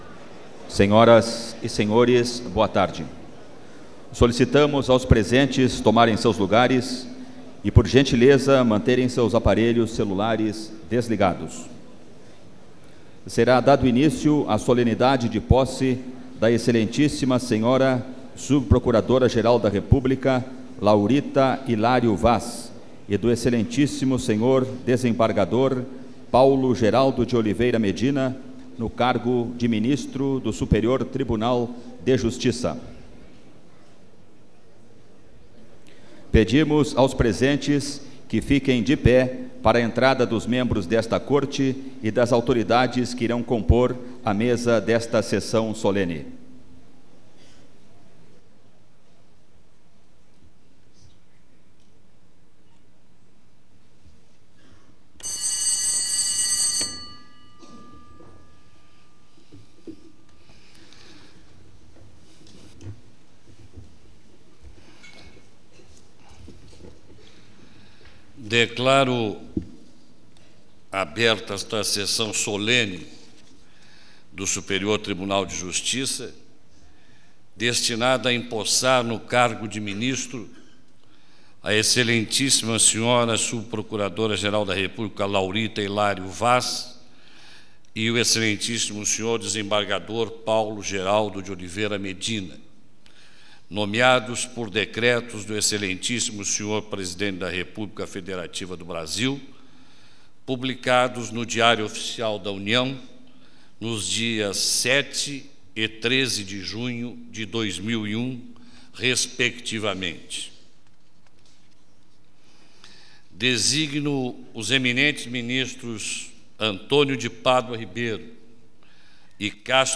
Solenidade realizada na Sala de Sessões Plenárias do STJ, em Brasília, em 26 de junho de 2001.